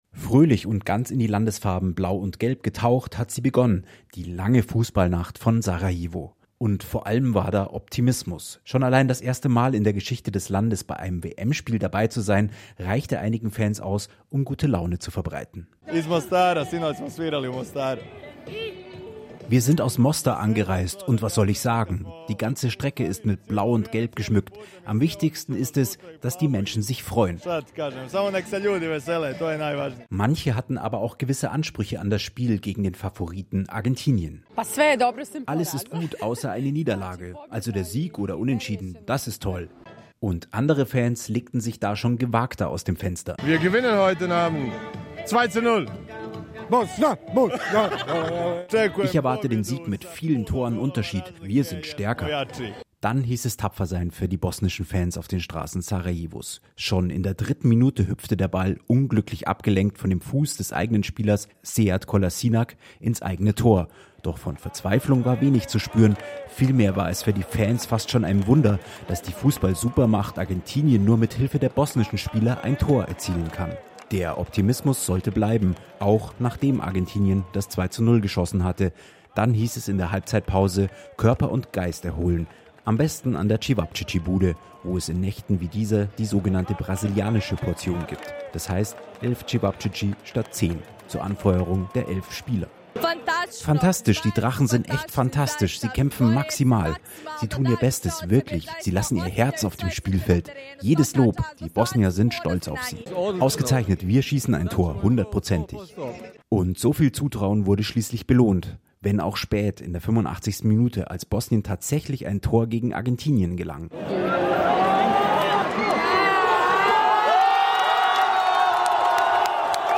Sarajevos-lange-Fußballnacht-Wie-Fans-das-1-zu-2-ihrer-Mannschaft-feiern.mp3